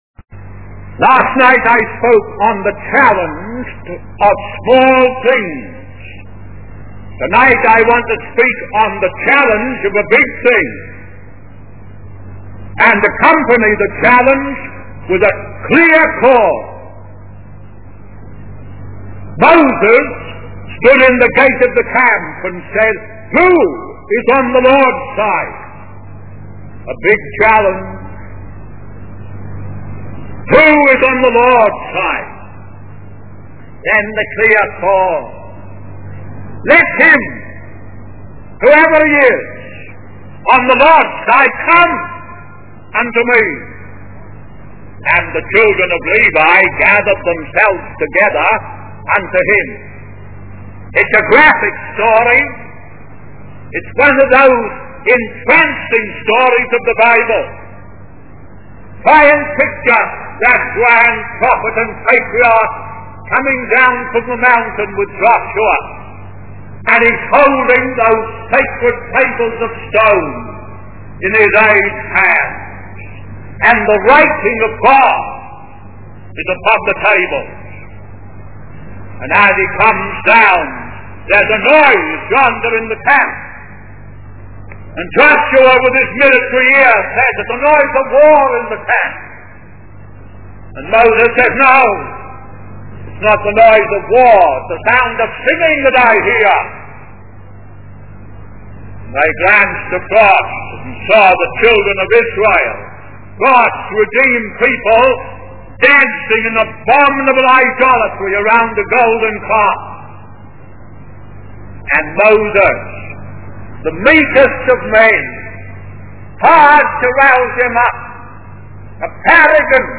In this sermon, the speaker discusses the challenge of choosing between the Lord's side and the side of the world. He tells the story of Moses coming down from the mountain with the Ten Commandments and witnessing the Israelites worshiping a golden calf.